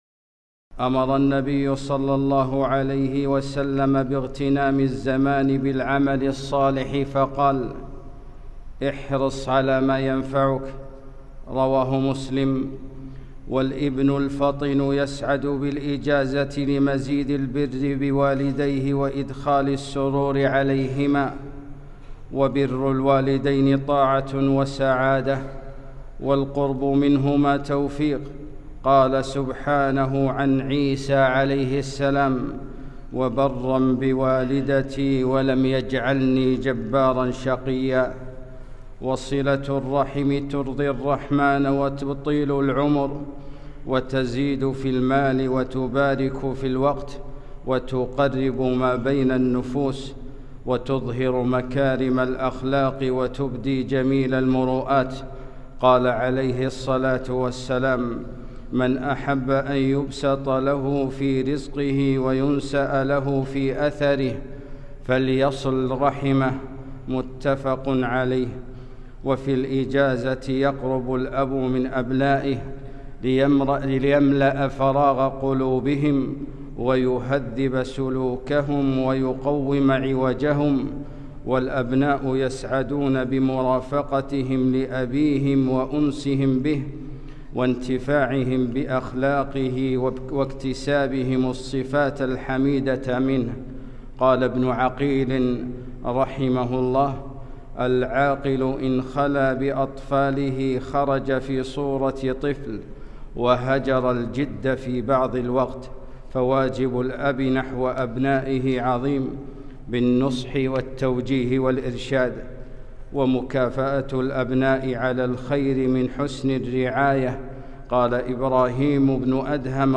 خطبة - الأولاد والإجازة